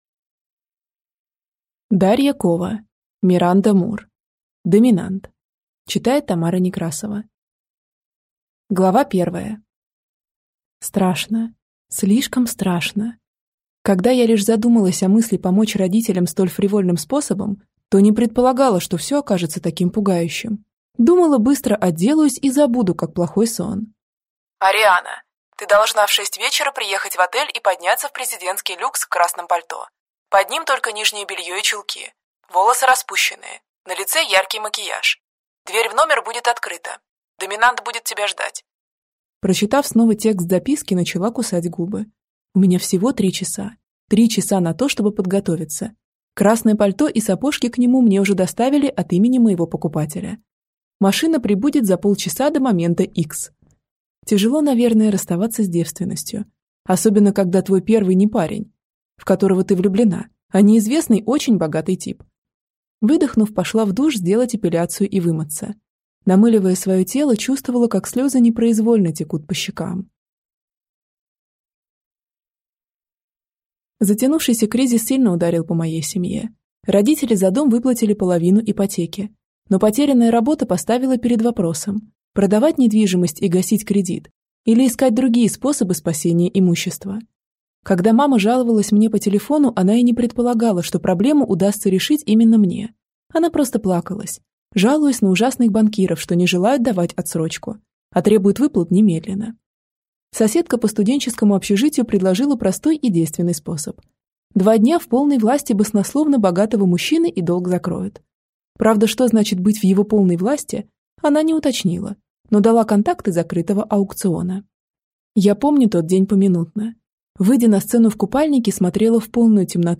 Аудиокнига Доминант | Библиотека аудиокниг
Прослушать и бесплатно скачать фрагмент аудиокниги